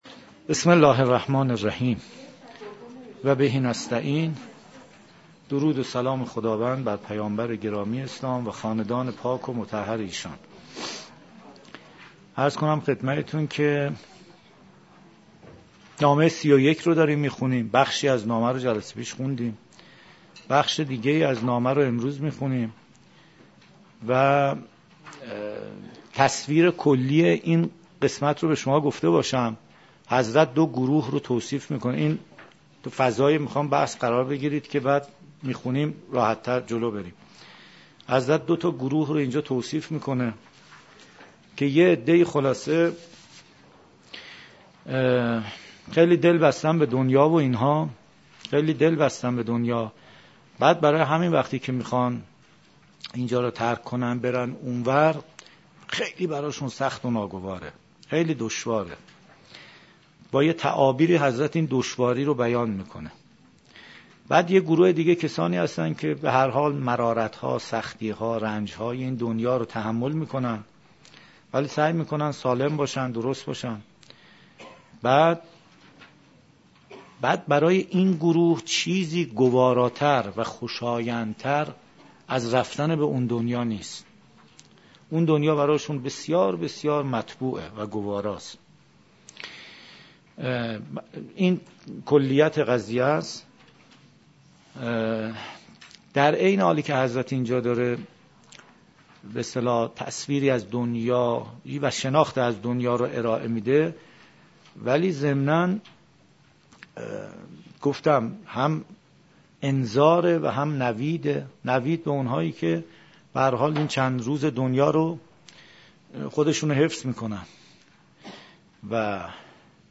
033 - تلاوت قرآن کریم